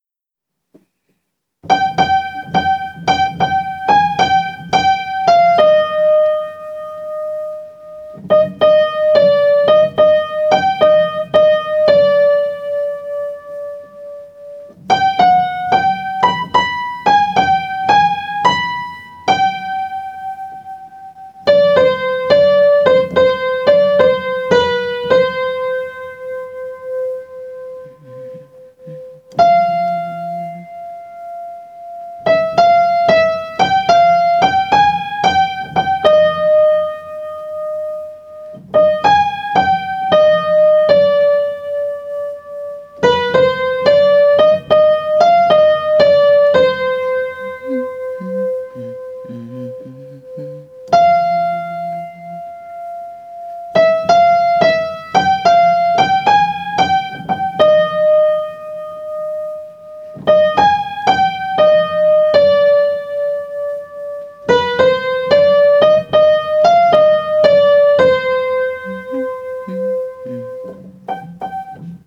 les sons pour apprendre, par vos cheffes préférées